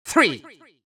countIn3Farther.wav